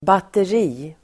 Uttal: [bater'i:]